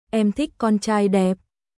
Em thích con trai đẹpイケメンが好きですエム・ティック・コンチャーイ・デップ🔊